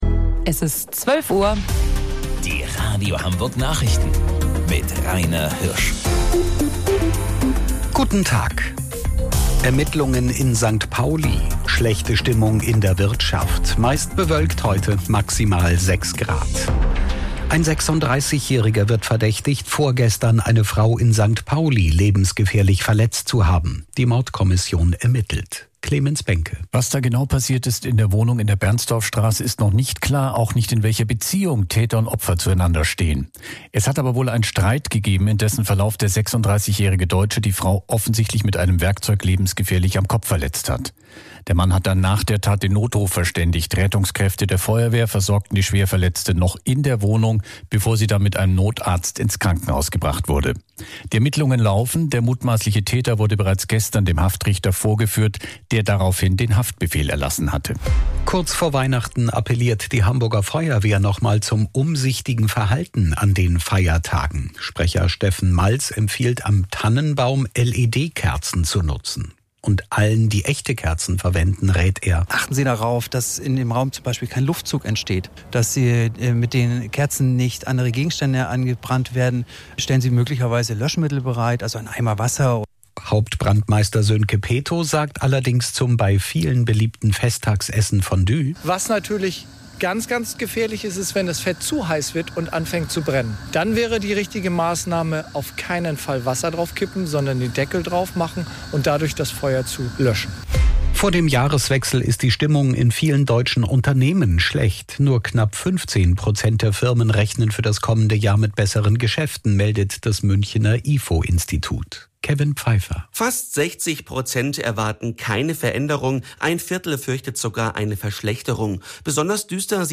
Radio Hamburg Nachrichten vom 22.12.2025 um 12 Uhr